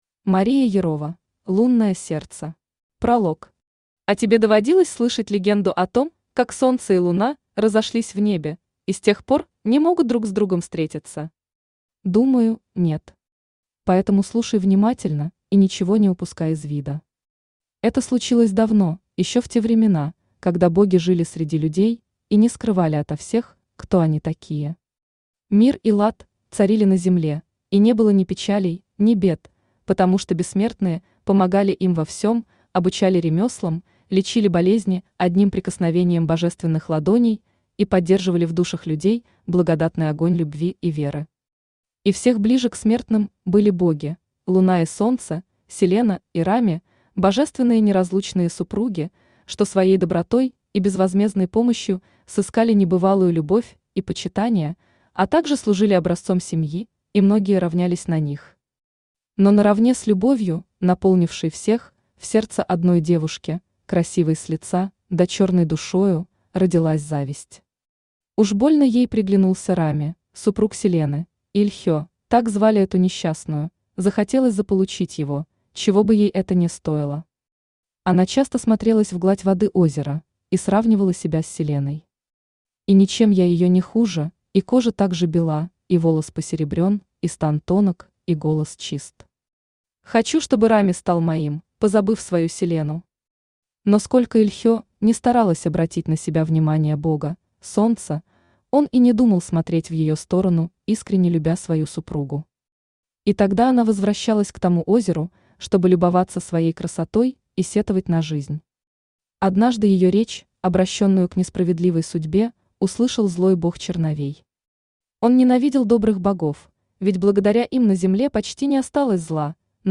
Аудиокнига Лунное сердце | Библиотека аудиокниг
Aудиокнига Лунное сердце Автор Мария Александровна Ерова Читает аудиокнигу Авточтец ЛитРес.